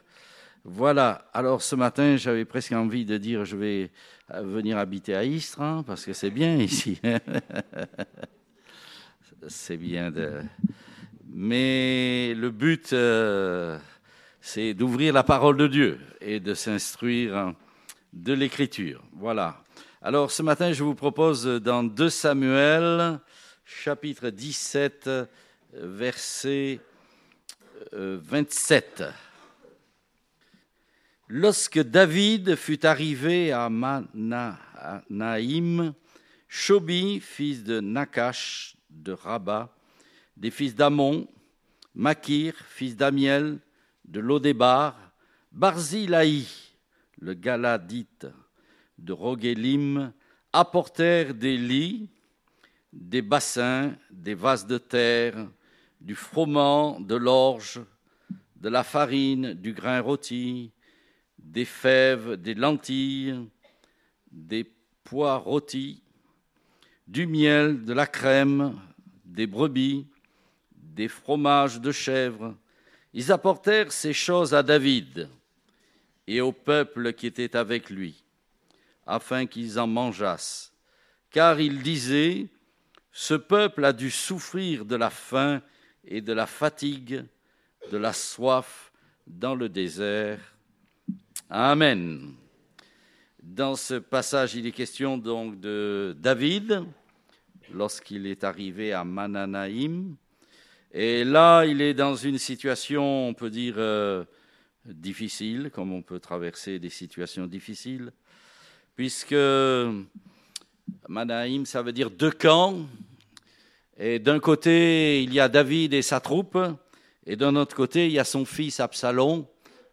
Date : 21 janvier 2018 (Culte Dominical)